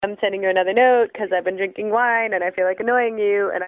Drinking wine